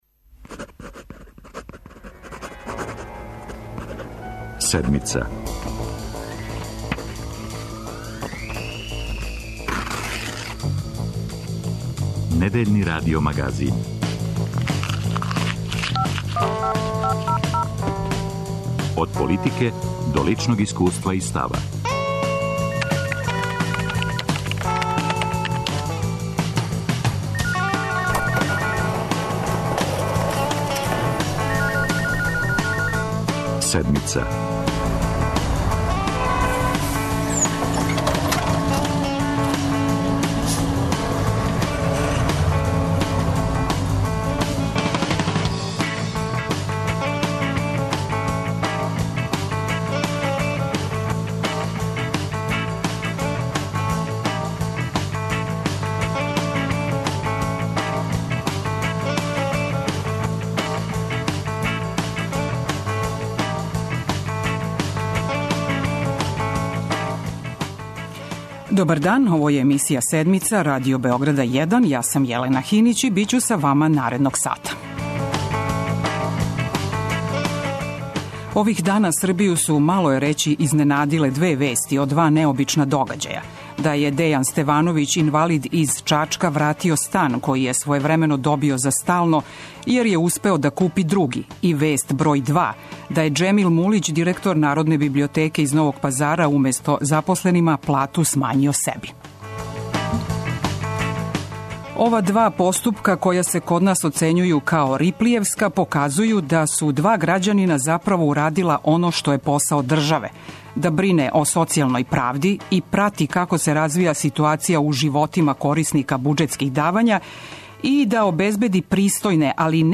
Стање у правосуђу није добро, али биће боље, на нас мотри Европска унија, каже за 'Седмицу' Министар правде Никола Селаковић.